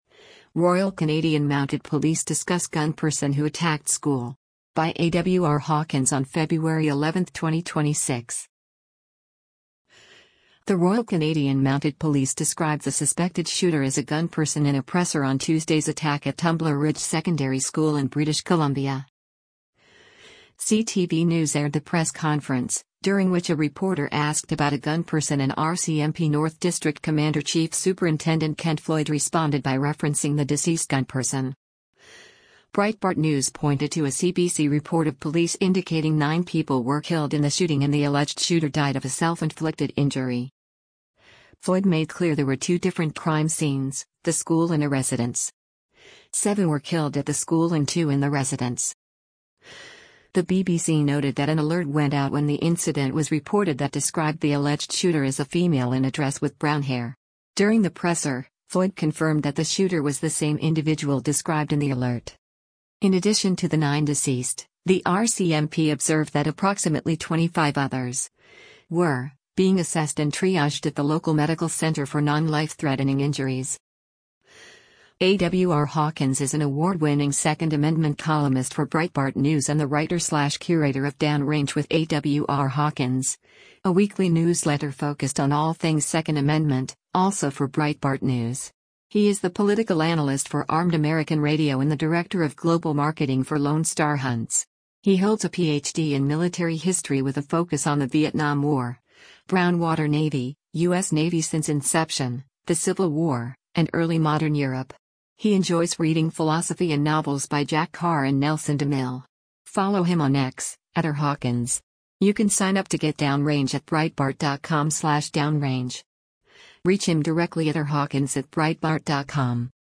CTV News aired the press conference, during which a reporter asked about a “gunperson” and RCMP North District Commander Chief Superintendent Ken Floyd responded by referencing “the deceased gunperson.”